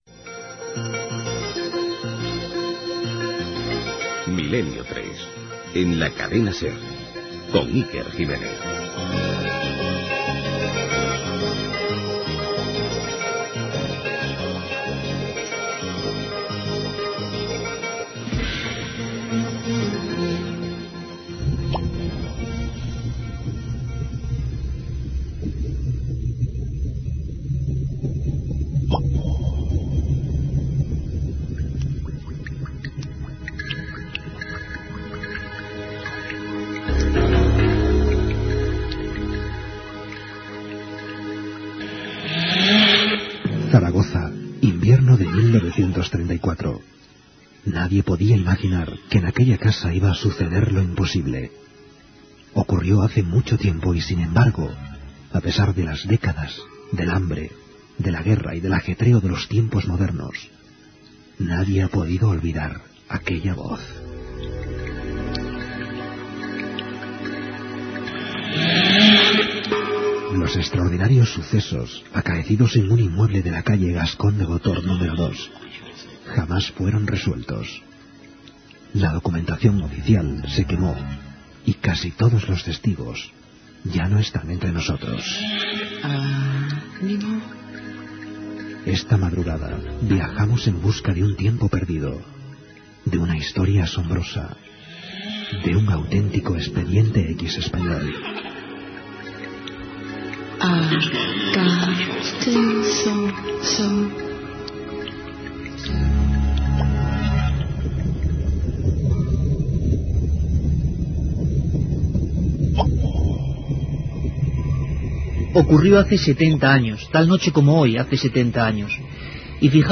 Indicatiu del programa, relat sobre successos ocorreguts a Saragossa l'any 1934.
Divulgació